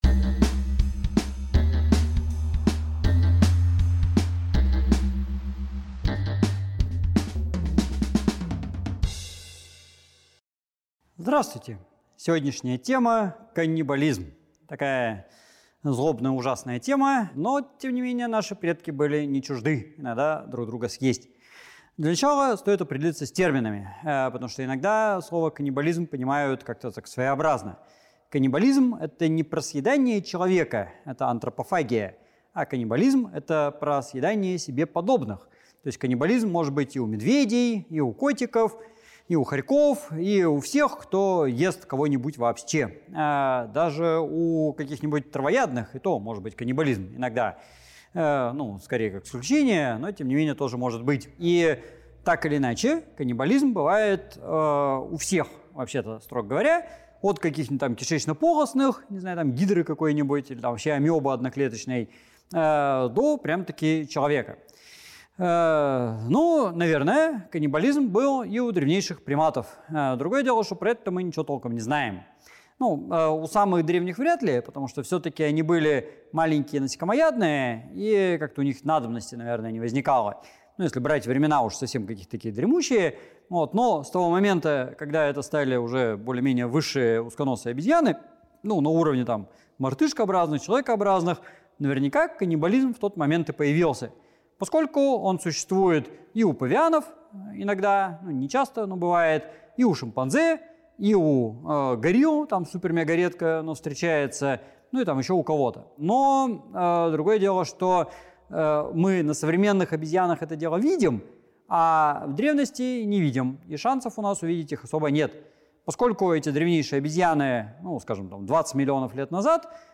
В эфире RTVI Станислав Дробышевский рассказывает о науке и человечестве